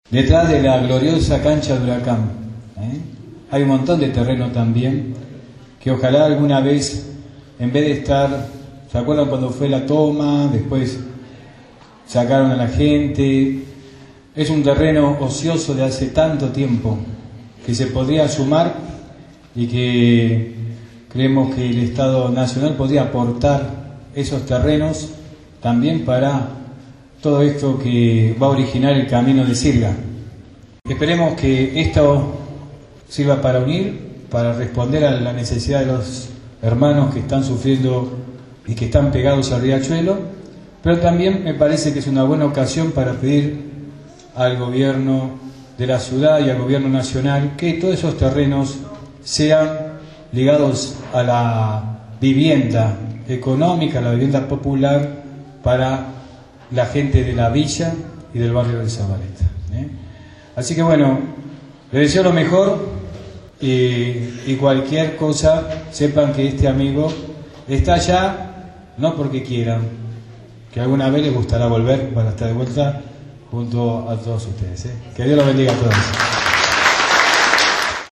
El sábado 18 de agosto en la escuela Lafayette de Barracas se realizó un encuentro por el conflicto por la relocalización de las familias que viven en el camino de sirga.
El Padre Pepe Di Paola hizo la apertura del encuentro